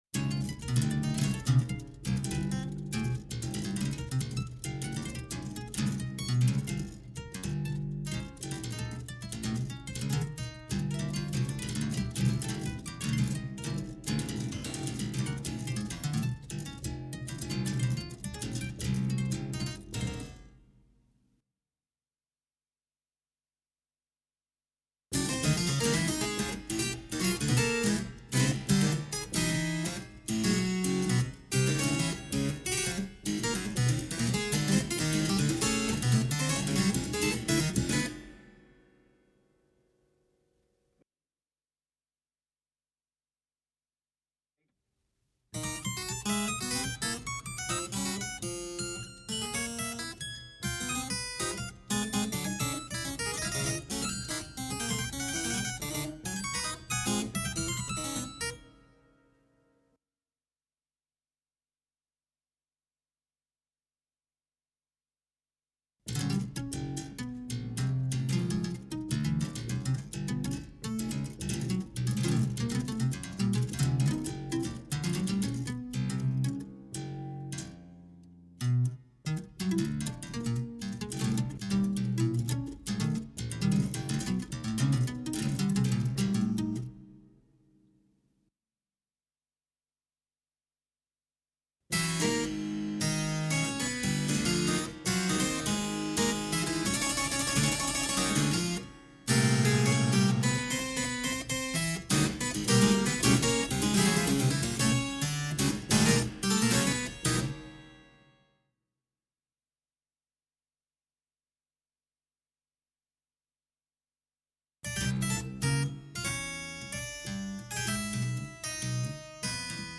registrato presso Studio Suono Ripetta,